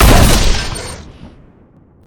shoot3.ogg